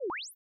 Custom synthesized sounds — no generic notification bleeps here:
▶ Play Rising "whaaa?" — agent needs your input
question.wav